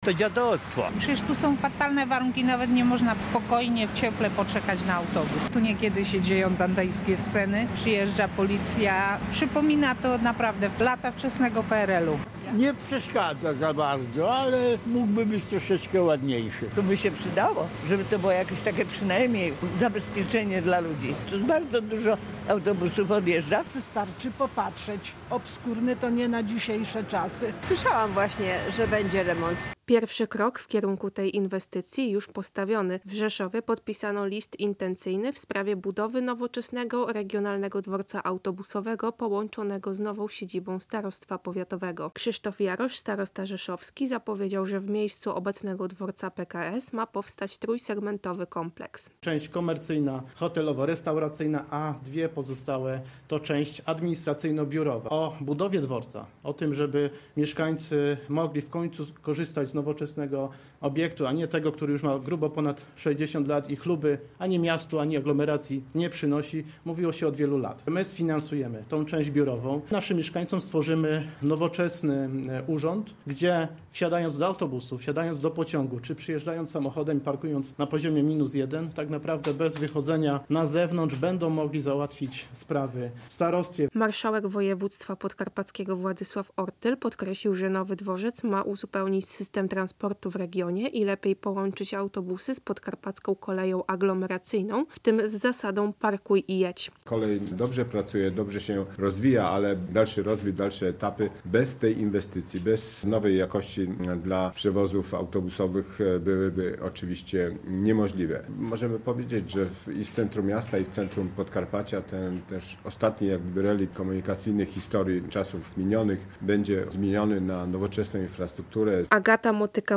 Relacje reporterskie • W miejscu obecnego dworca PKS w Rzeszowie ma powstać nowoczesny Regionalny Dworzec Autobusowy połączony z nową siedzibą Starostwa Powiatowego. Inwestycja ma kosztować ponad 300 milionów złotych.